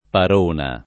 [ par 1 na ]